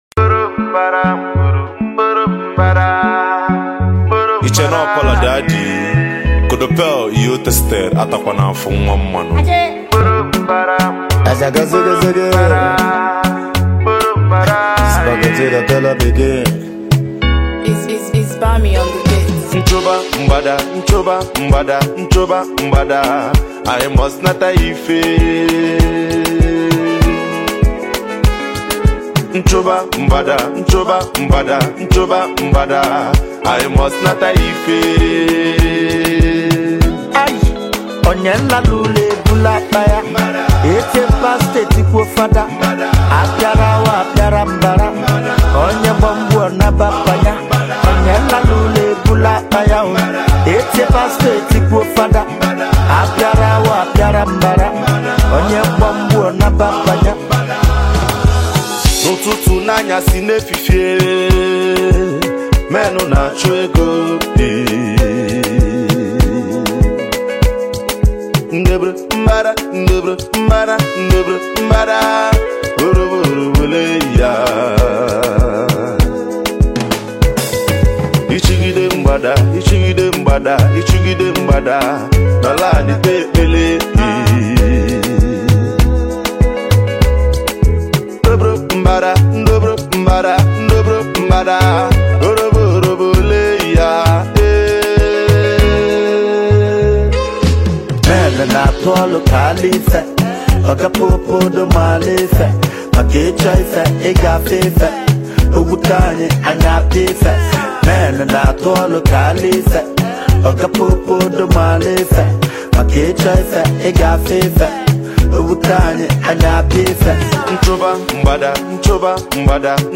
indigenous singer